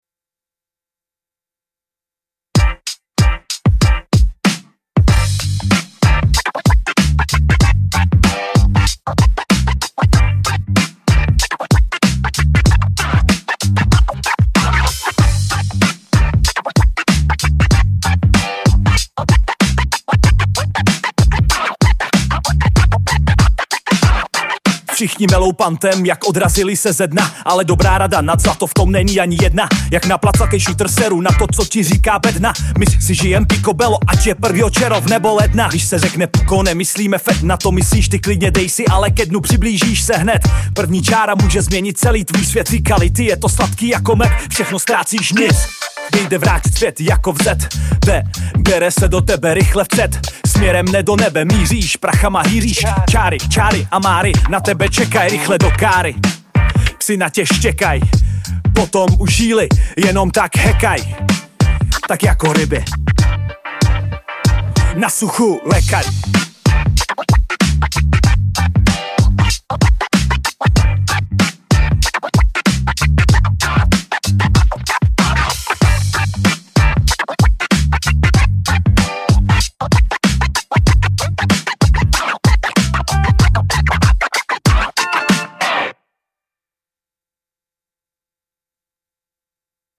Oldschool
Fun, lyrics with Oldschool style.
Generated track
Ready-to-play MP3 from ElevenLabs Music.